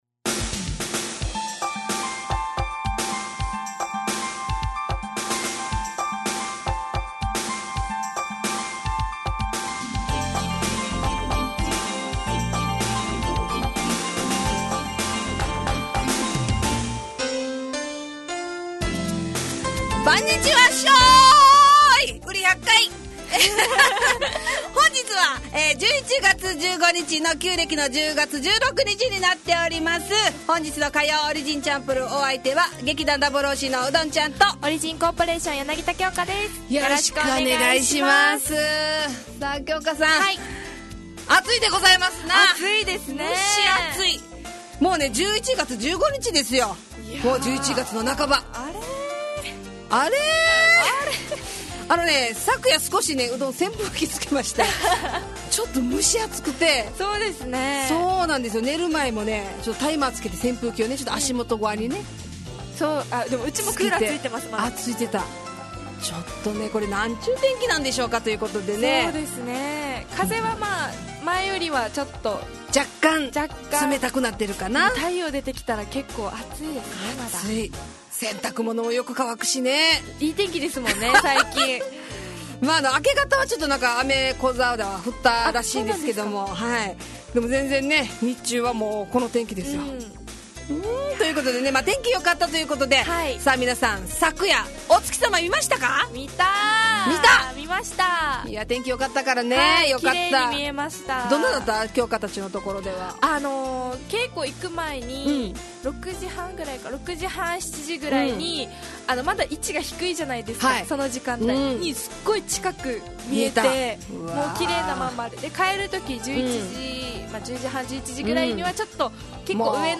fm那覇がお届けする沖縄のお笑い集団オリジンと劇団O.Z.Eメンバー出演のバラエティ番組